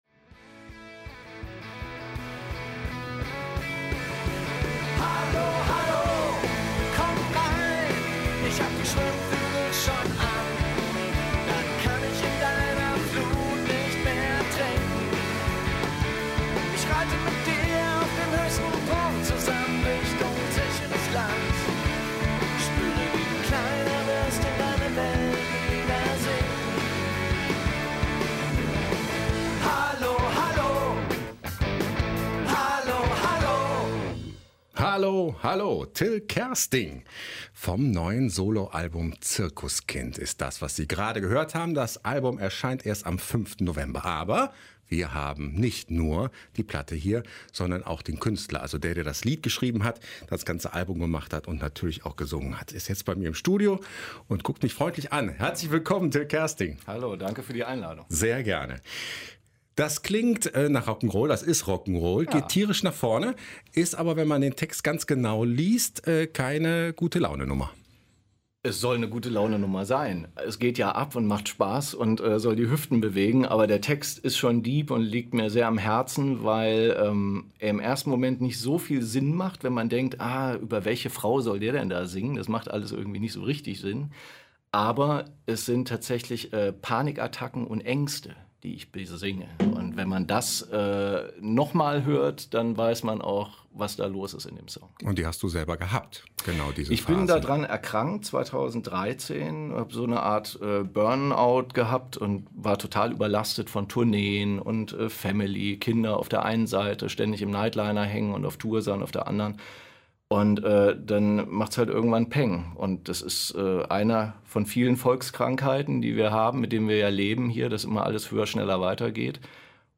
Im Interview erzählt er von seiner prägenden Zeit als echtes Zirkuskind.